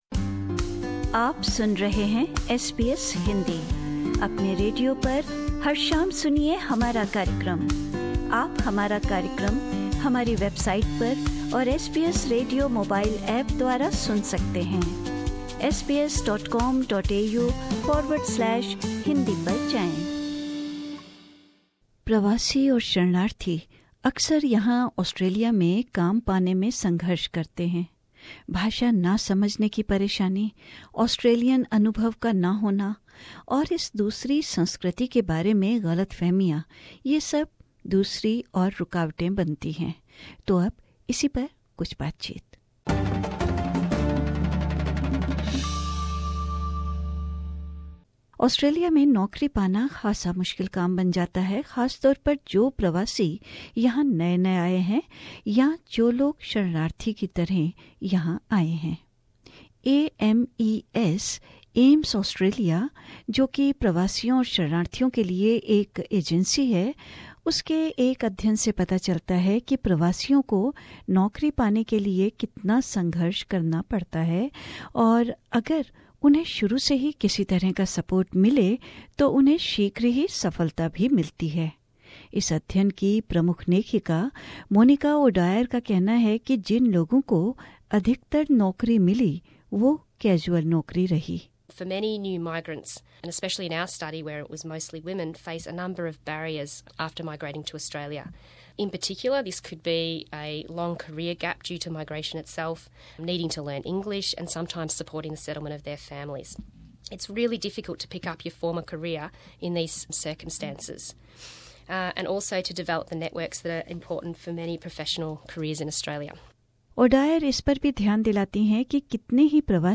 SBS हिन्दी